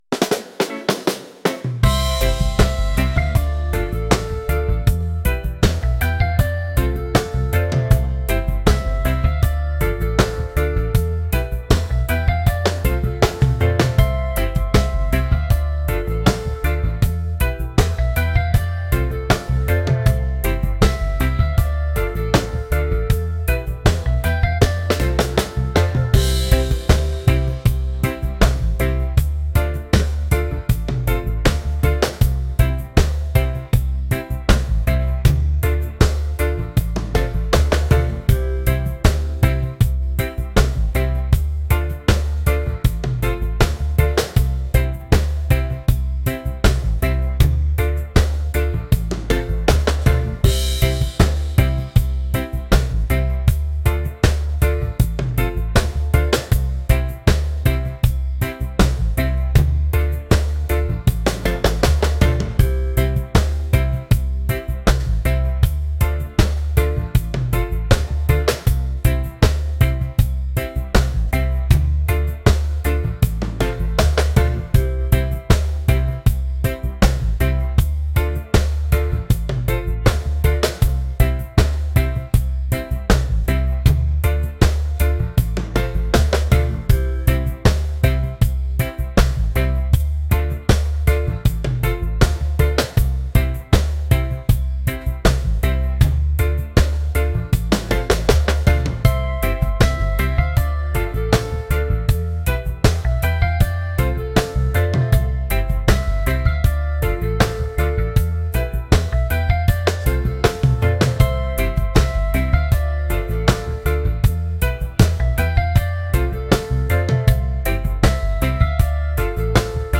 reggae | romantic | laid-back